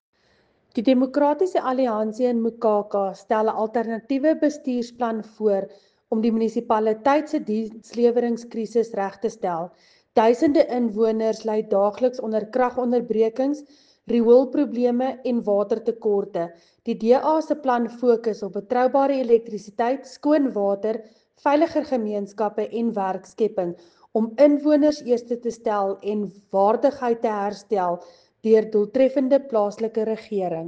Afrikaans soundbites by Cllr Linda Louwrens.